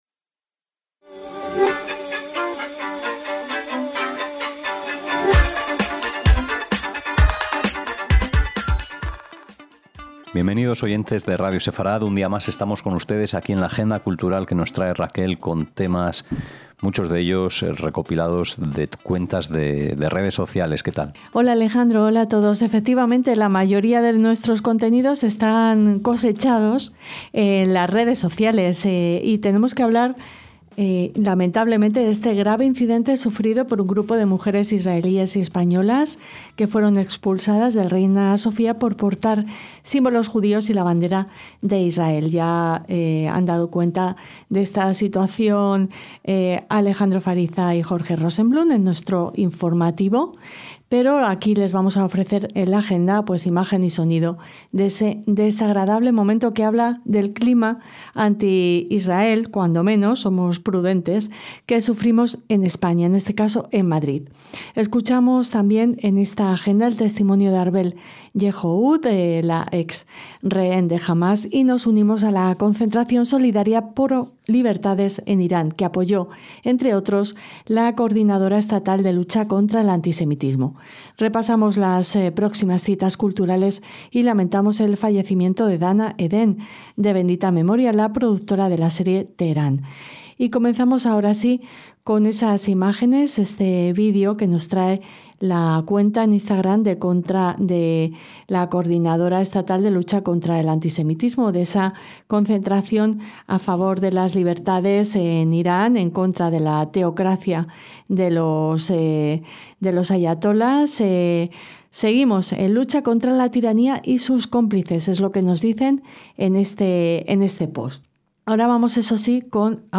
AGENDA RS - Tras el grave incidente sufrido por un grupo de mujeres israelíes y españolas que fueron expulsadas del Reina Sofía por portar símbolos judíos y la bandera de Israel les ofrecemos imagen y sonido del desagradable momento que habla del clima anti Israel (cuando menos) que sufrimos en España, en este caso en Madrid. Escuchamos el testimonio de Arbel Yehoud y nos unimos a la concentración solidaria pro libertades en Irán que apoyó, entre otros, la Coordinadora Estatal de Lucha contra el Antisemitismo. Repasamos las próximas citas culturales y lamentamos el fallecimiento de Dana Eden Z''L, (en portada) la productora de la serie Teherán.